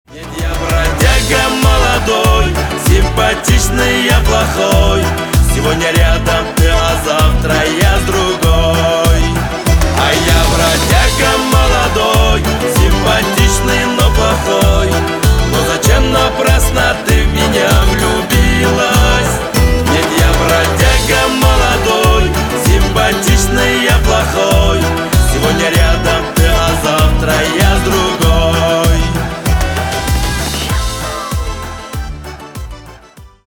Поп Музыка # кавказские